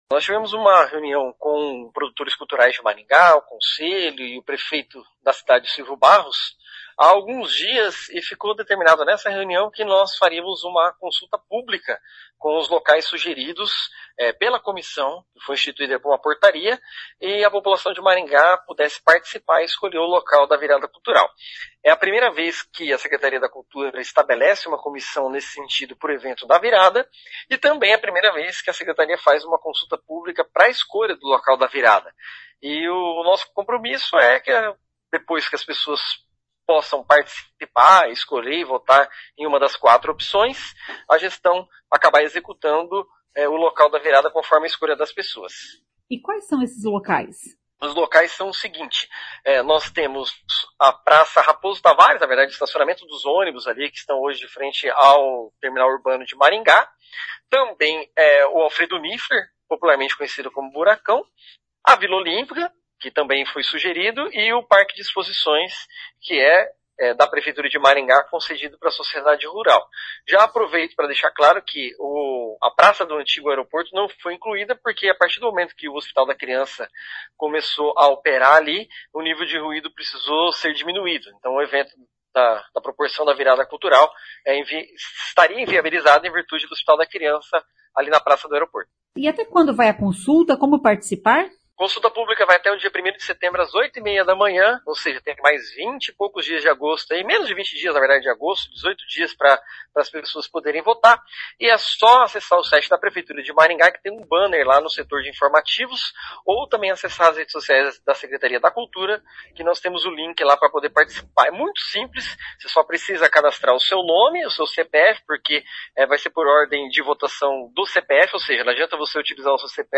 A consulta oferece quatro opções de espaços, entre eles o próprio Parque de Exposições. Ouça o que diz o secretário Tiago Valenciano.